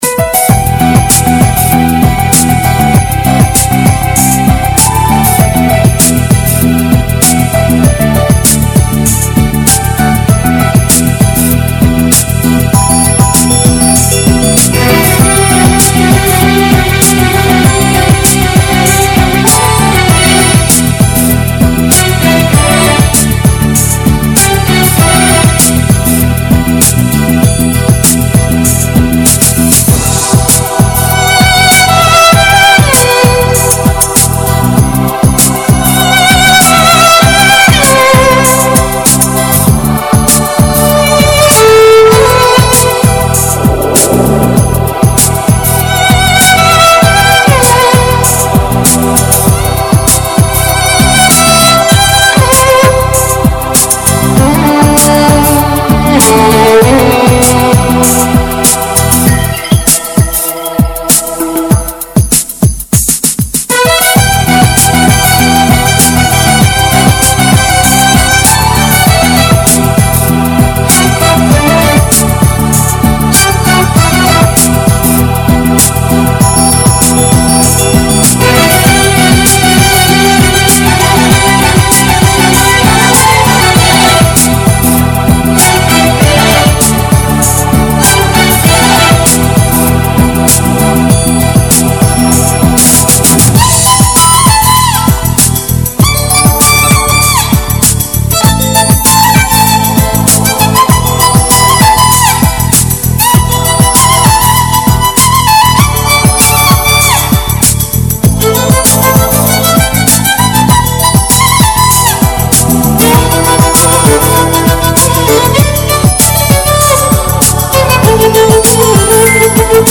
Скрипка - самая красивая инструментальная музыка
Skripka-_-samaya-krasivaya-instrumentaljqnaya-muzyka.mp3